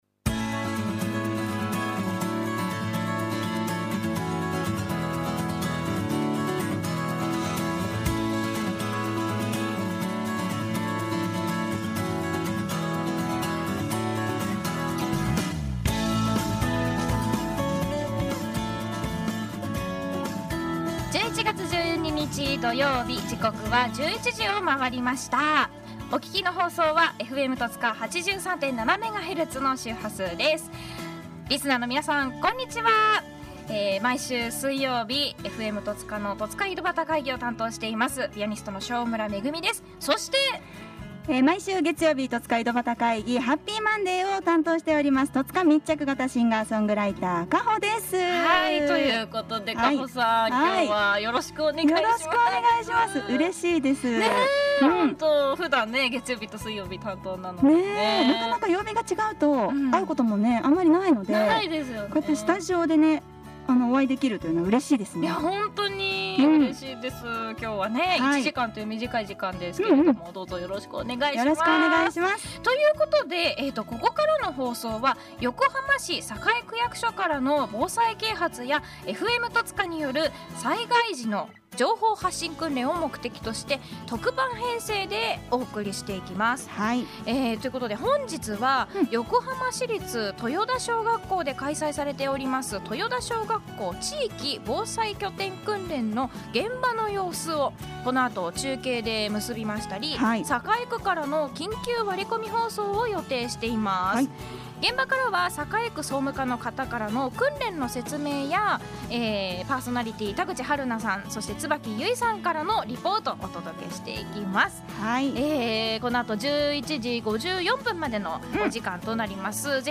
Na cooperação com de Tozuka, informei a um participante e a pessoa preocupada por uma furadeira de prevenção de desastre executada na Escola de Cidade Yokohama toyota elementar no dia 12 de novembro de 2022 e fazia-o transmiti-lo por um programa no 25o do programa especial no dia e 21 de novembro.